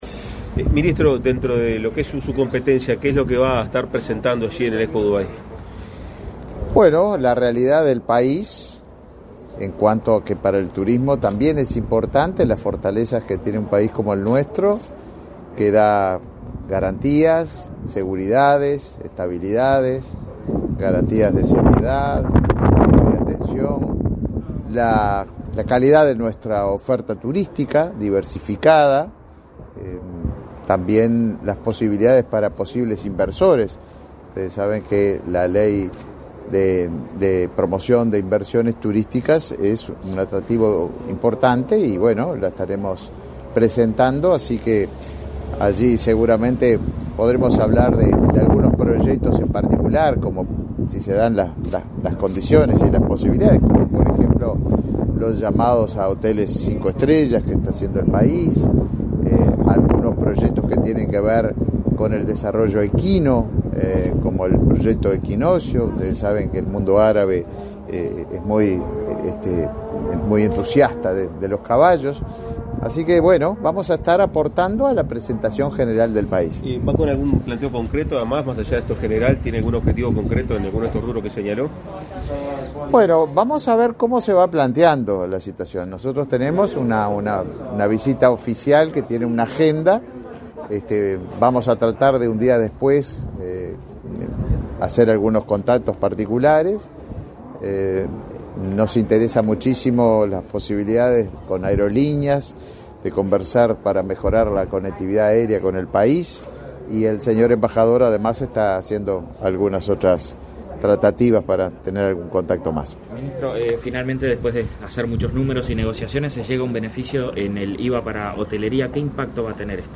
Entrevista al minsitro de Turismo, Tabaré Viera